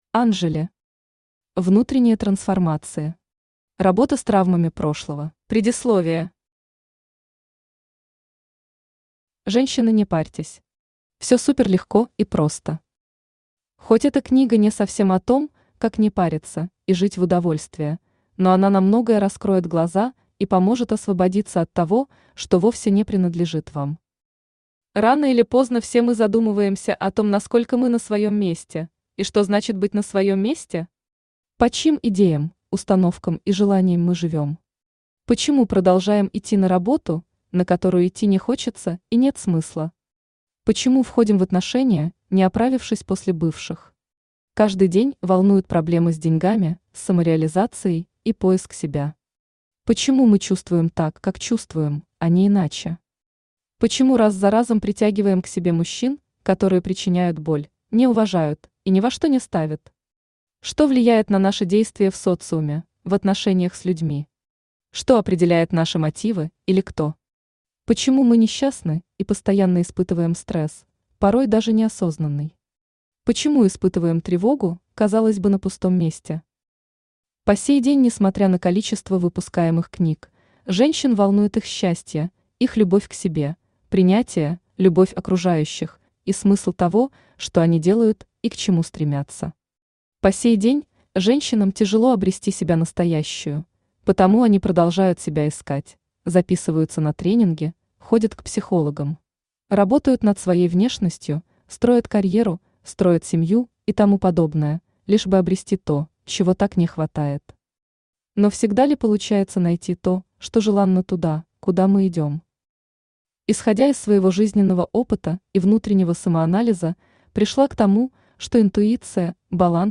Aудиокнига Внутренние трансформации. Работа с травмами прошлого Автор Анжели Читает аудиокнигу Авточтец ЛитРес.